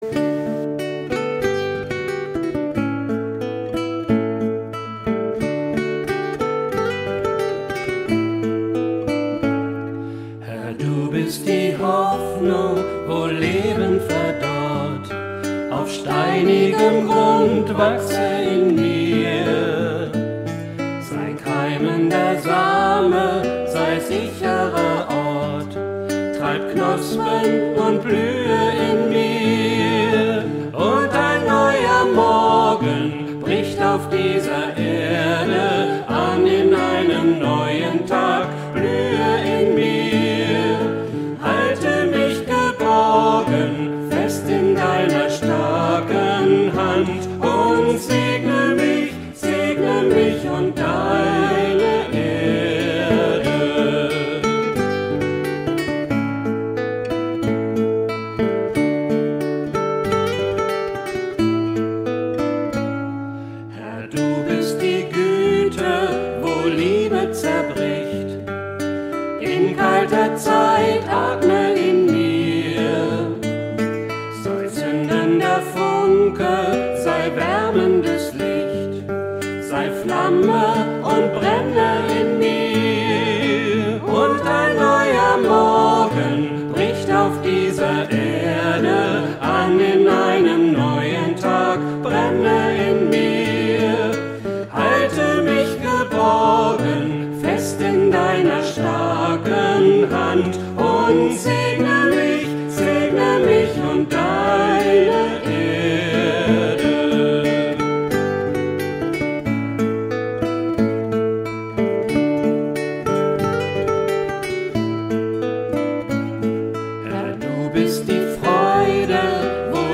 Die Audiodatei („Und ein neuer Morgen“, GL 707) stammt aus der Morgenandacht vom 7.4.2021